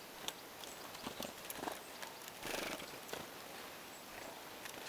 tuollaista jotain tiaisen ääntelyä
tuollaista_jotain_tiaisen_aantelya.mp3